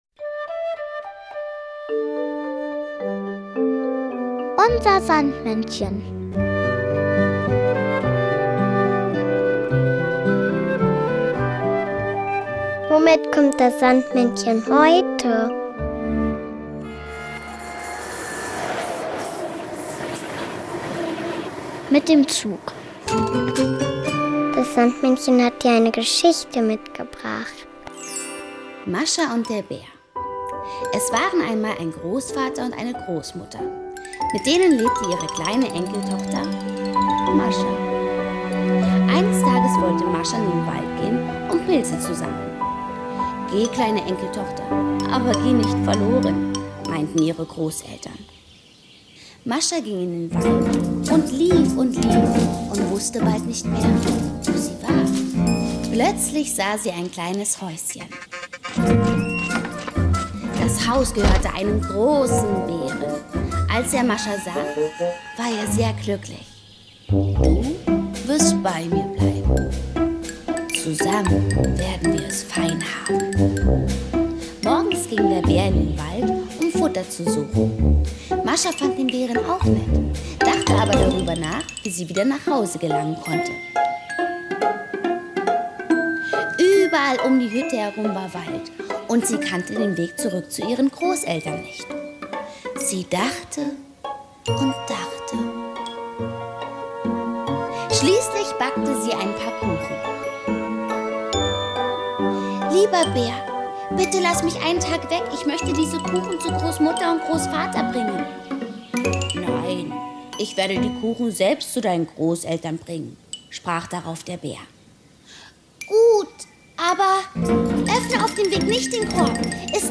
Märchen: Mascha und der Bär